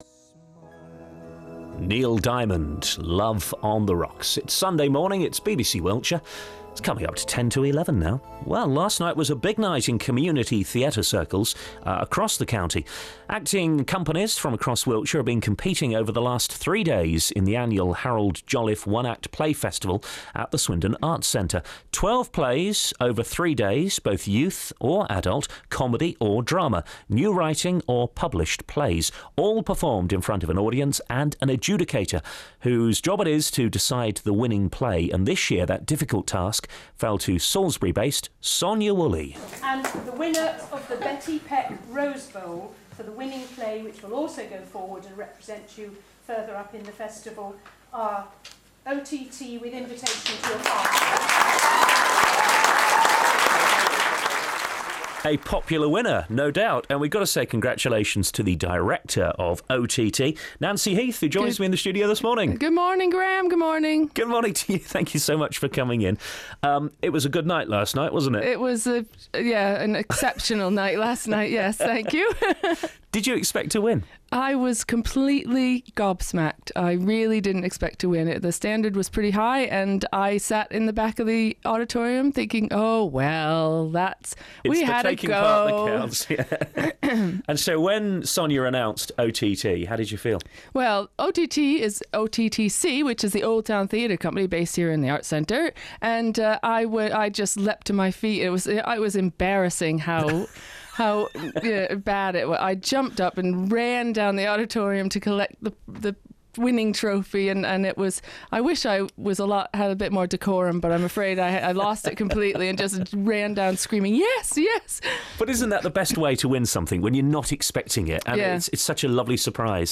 Interviewed on BBC Radio